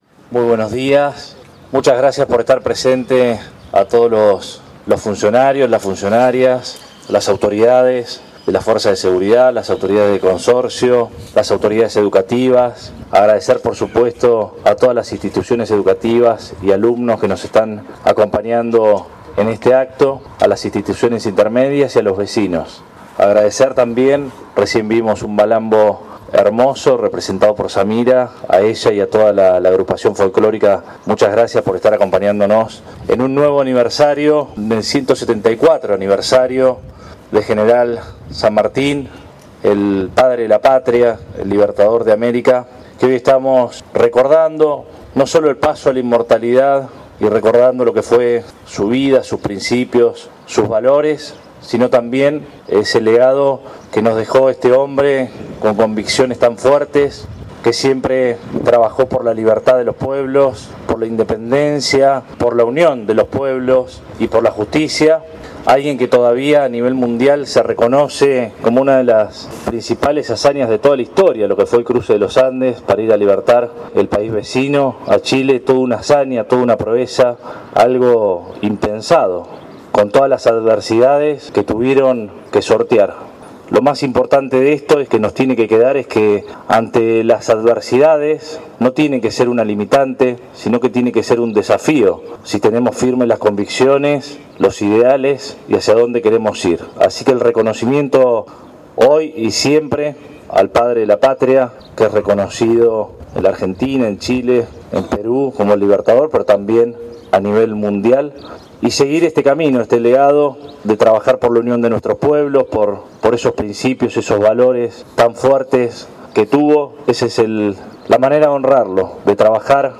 En la mañana de este sábado 17 de agosto, el intendente Arturo Rojas encabezó en emotivo acto en homenaje al General José de San Martín, al cumplirse el 174° Aniversario de su Paso a la Inmortalidad y en el monumento que le rinde homenaje, en la intersección de Avenida 59 y la Diagonal que lleva su nombre.
17-08-AUDIO-Arturo-Rojas-Paso-Inmortalidad-San-MArtin.mp3